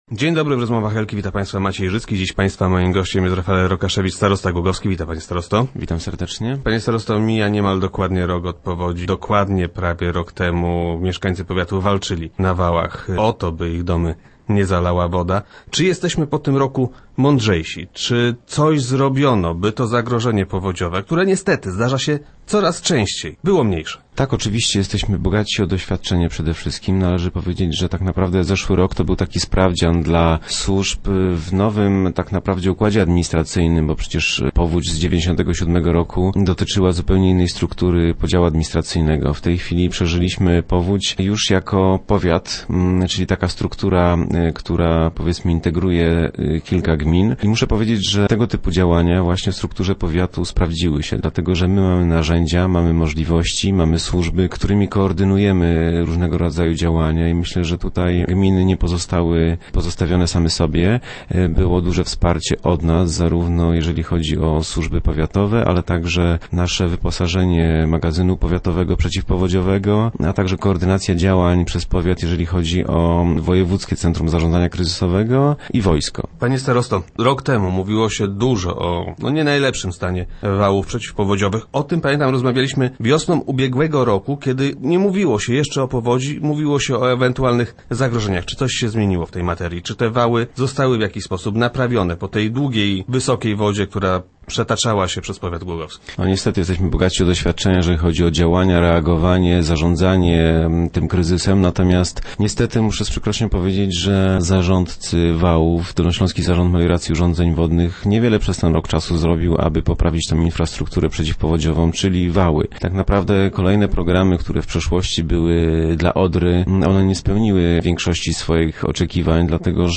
Czy rok po powodzi jesteśmy bardziej bezpieczni? Gościem Rozmów Elki był starosta Rafael Rokaszewicz.